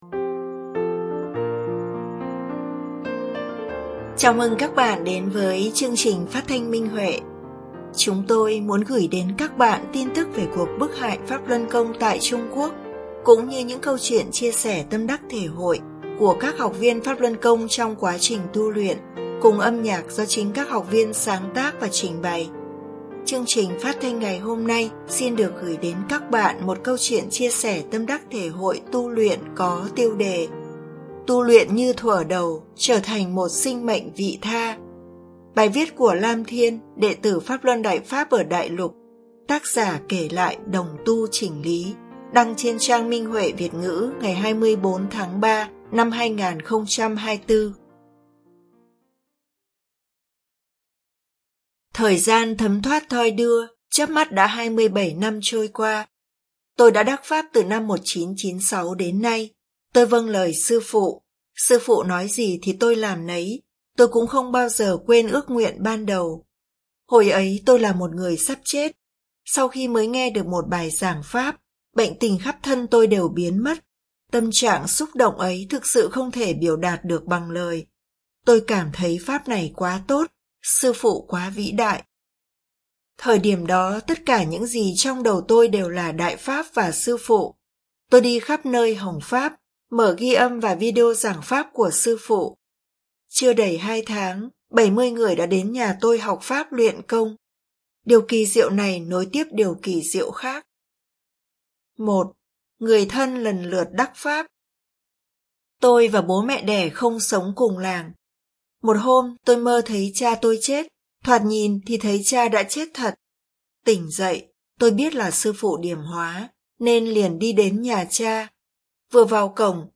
Chúng tôi muốn gửi đến các bạn thông tin về cuộc bức hại Pháp Luân Côngtại Trung Quốc cũng như những câu chuyện chia sẻ tâm đắc thể hội của các học viên trong quá trình tu luyện, cùng âm nhạc do chính các học viên sáng tác và trình bày.